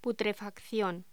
Locución: Putrefacción
voz
Sonidos: Voz humana